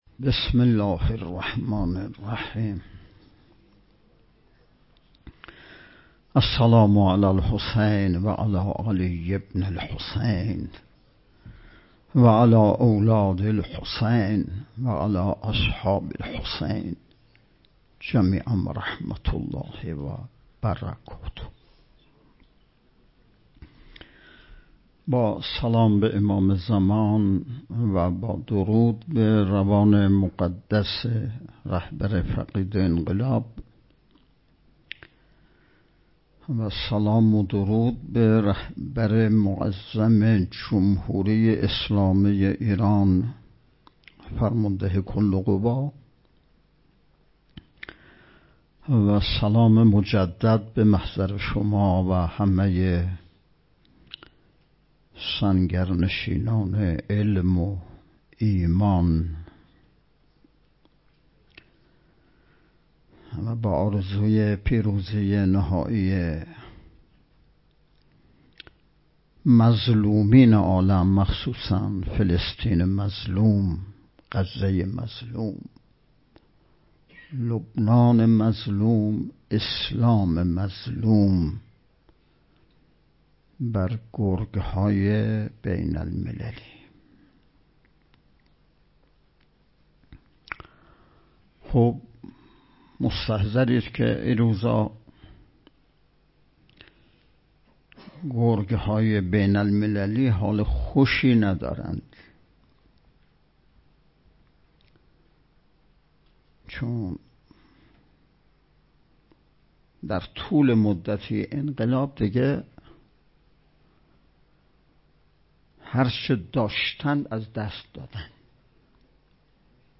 نوزدهمین نشست ارکان شبکه تربیتی صالحین بسیج با موضوع تربیت جوان مؤمن انقلابی پای کار، صبح امروز (۱۹ تیرماه) با حضور و سخنرانی نماینده ولی فقیه در استان، برگزار شد.